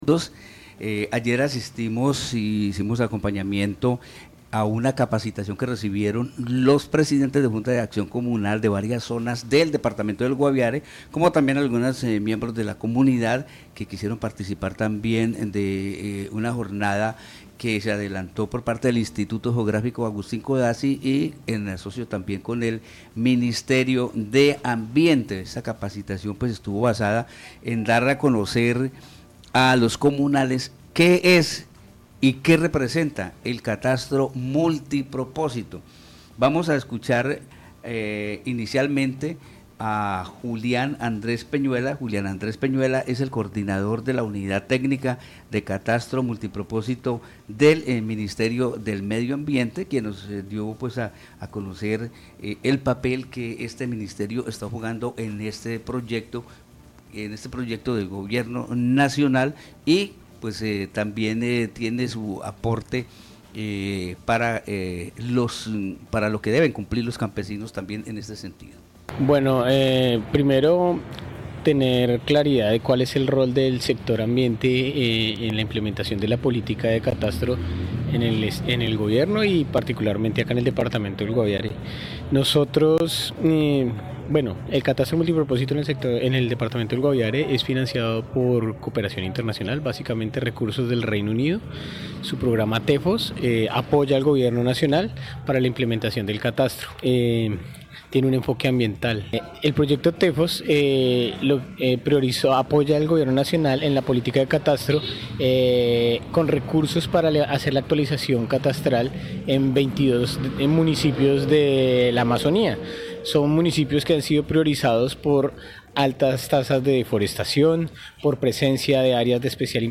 Hablamos con los protagonistas de esta formación para conocer cómo ha sido la aceptación hasta ahora por parte de la comunidad de esta propuesta del gobierno nacional.